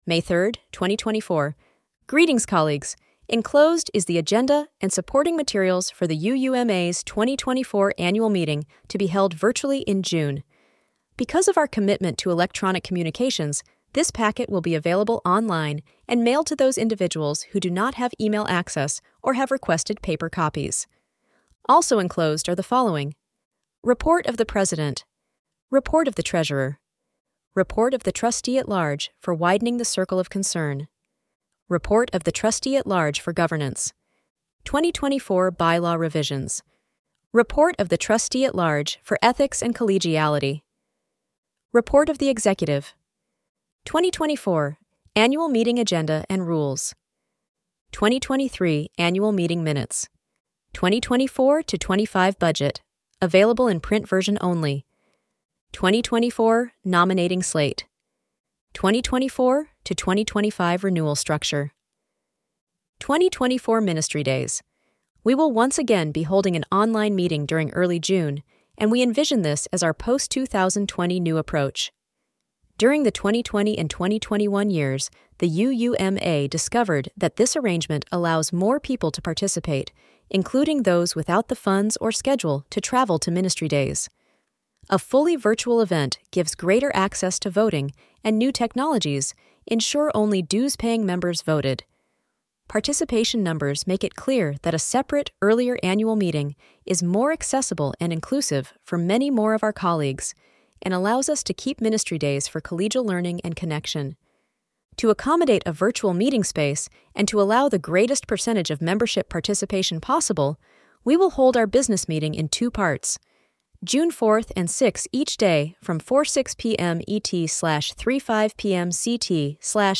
Audio Version of the Annual Packet : This week’s podcast episode is an AI generated reading of the 2024 Annual Meeting packet. Charts (such as the Budget and Dues charts) have been removed for ease of listening.